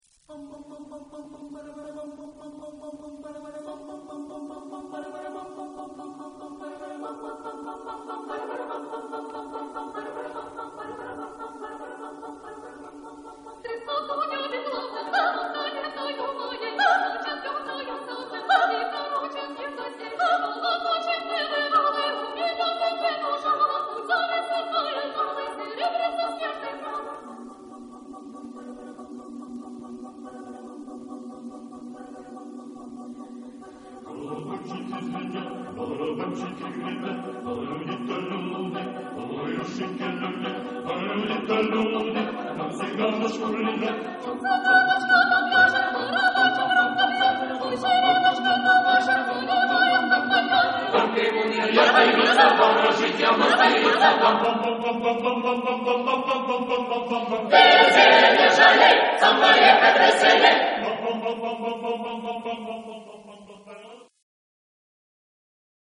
Genre-Style-Form: Cantata
Mood of the piece: whimsical ; playful
Type of Choir: SATB + SATB  (8 double choir OR mixed voices )
Soloist(s): soprano (3)  (3 soloist(s))
Tonality: D major
Discographic ref. : Internationale Koorwedstrijd van Vlaanderen-Maasmechelen, 1999